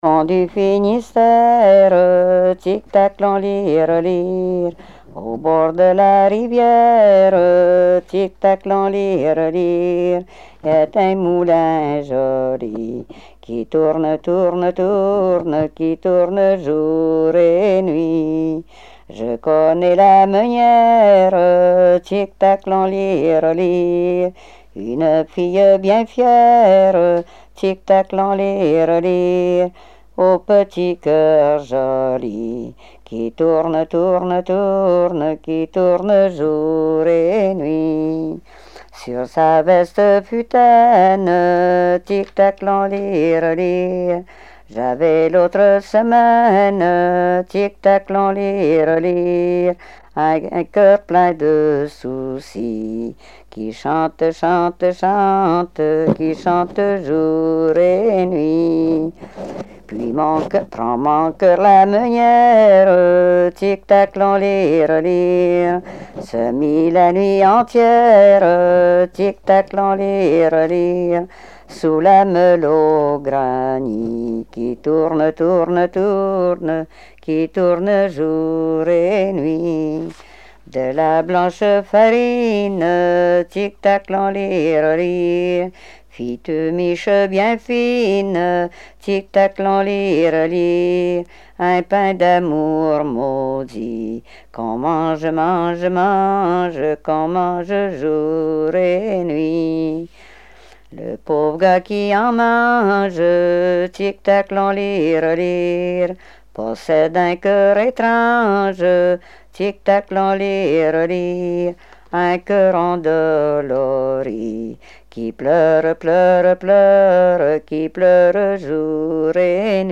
Genre strophique
Répertoire de chansons traditionnelles et populaires
Pièce musicale inédite